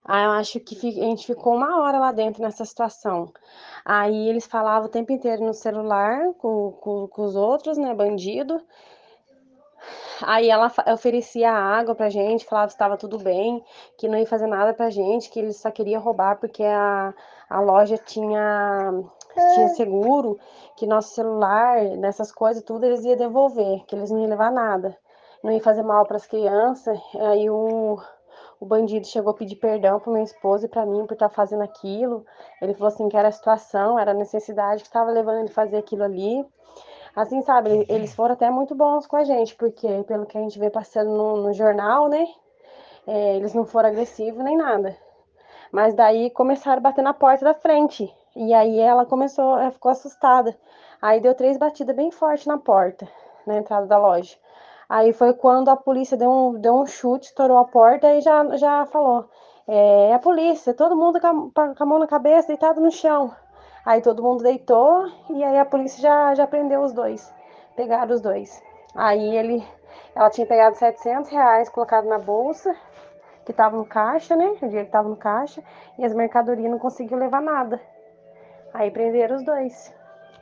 Ouça o relato de quem estava na loja.